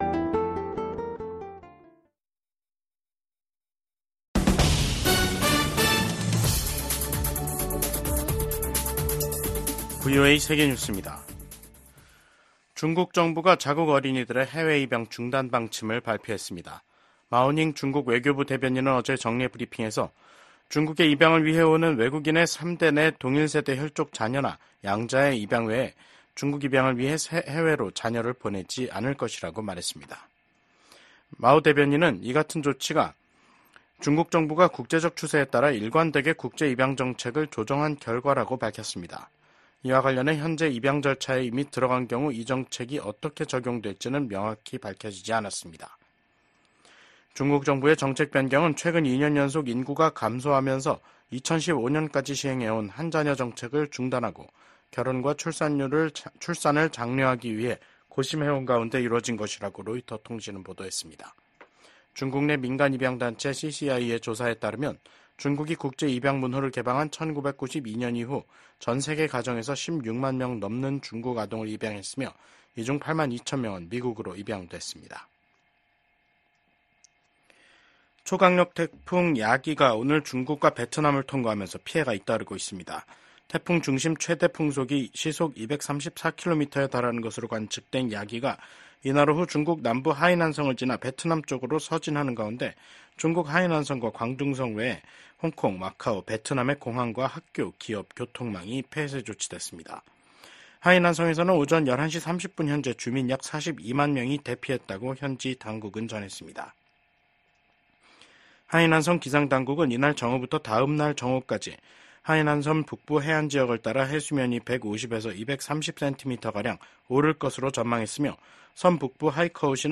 VOA 한국어 간판 뉴스 프로그램 '뉴스 투데이', 2024년 9월 6일 2부 방송입니다. 윤석열 한국 대통령은 퇴임을 앞두고 방한한 기시다 후미오 일본 총리와 정상회담을 가졌습니다.